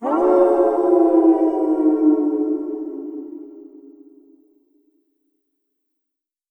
mixkit-wolves-pack-howling-1776.wav